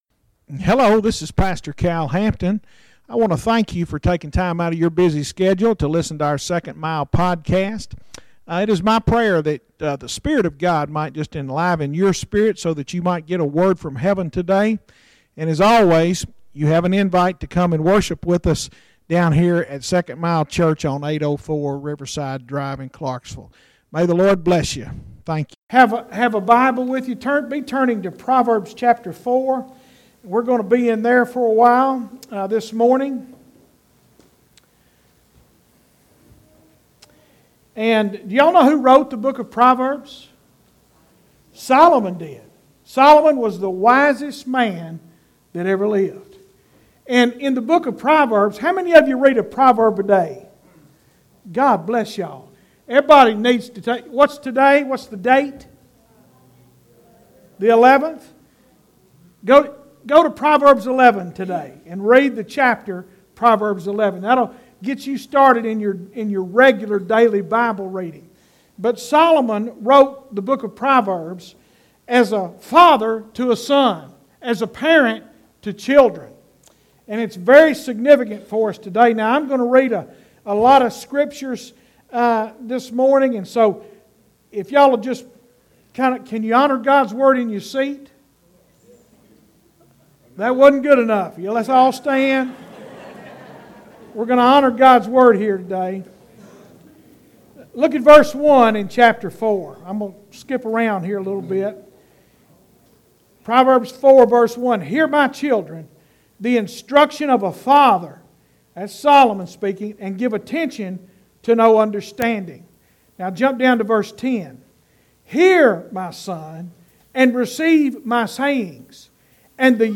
SERMONS - 2nd Mile Church